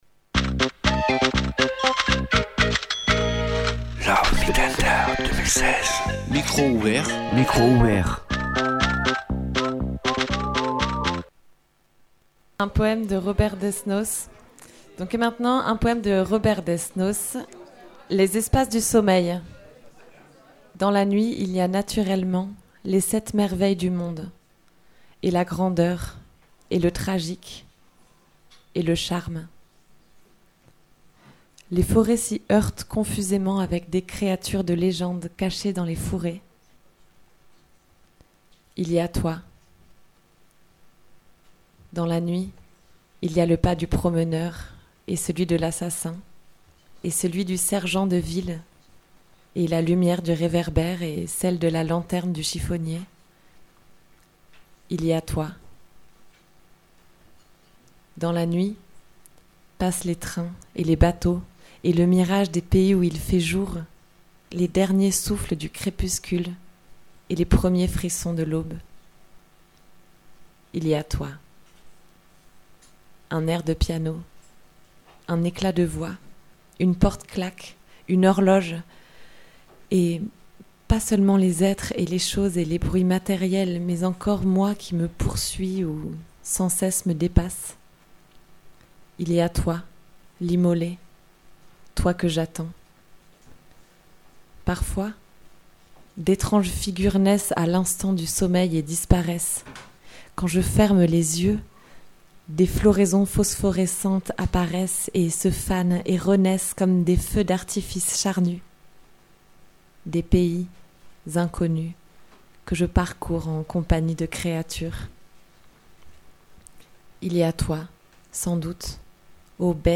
24 heures non stop de lecture radiophonique de lettres d'amour le 14 février 2106 à la Cave Poésie.